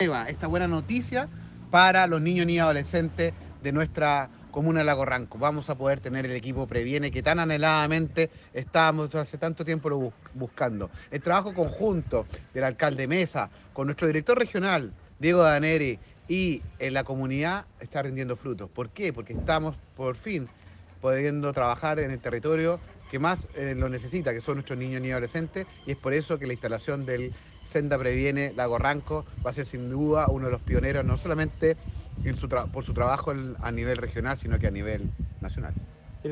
Cuna-Director-Nacional-SENDA-Carlos-Charme.mp3